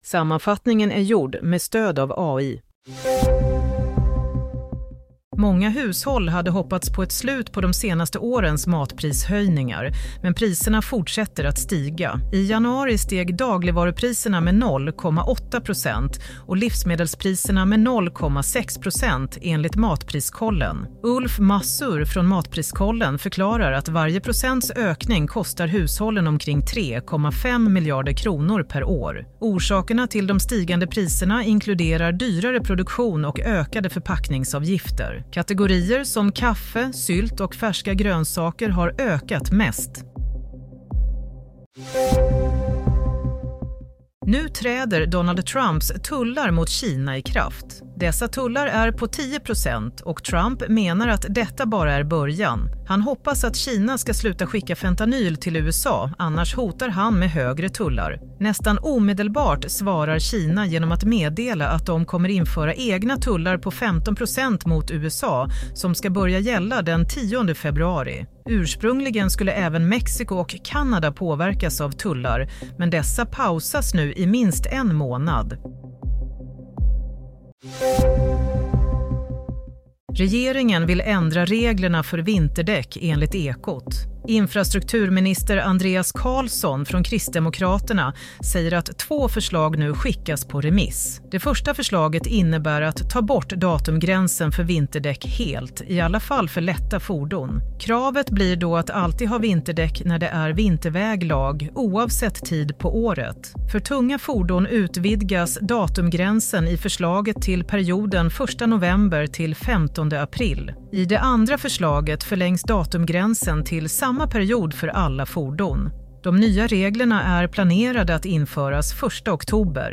Sammanfattningen av följande nyheter är gjord med stöd av AI. - Matpriserna rusar igen - Nu införs Trumps tullar mot Kina - Vinterdäck: Nya regler föreslås – två alternativ Redaktör